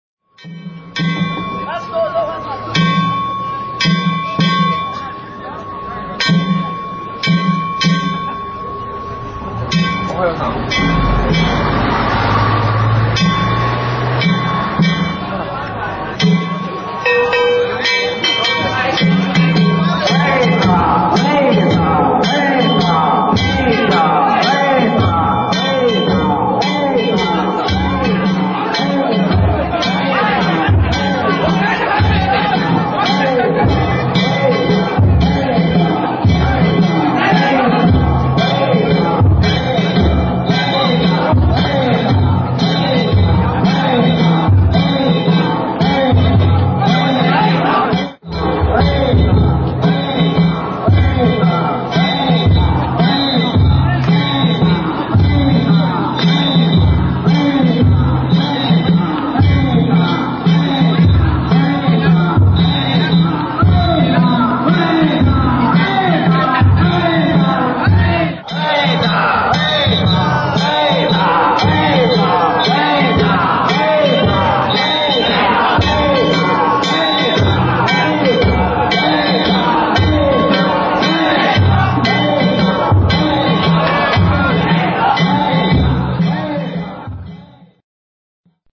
平成２８年２月２８日、堺市上神谷地区の泉田中地車入魂式を見に行ってきました。
鳴り物係、大工方が地車に乗り込みました。
鳴り物が始まり、まもなく出発です。